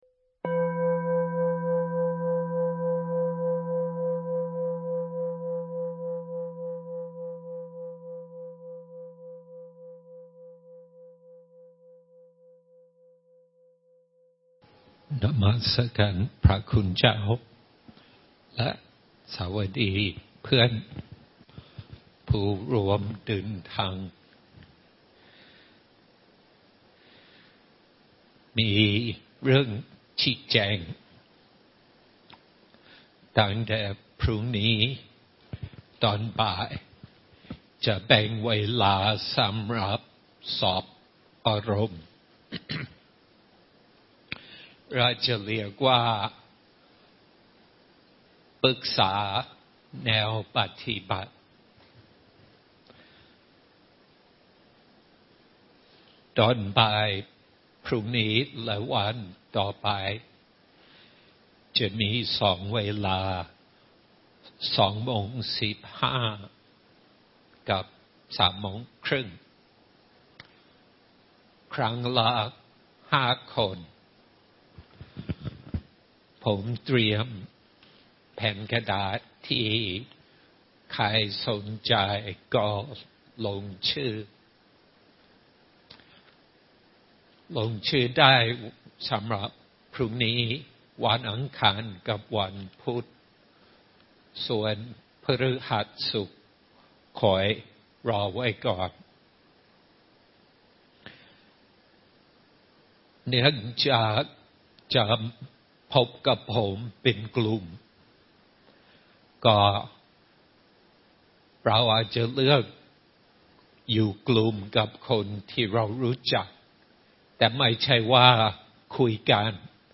Dhamma Talk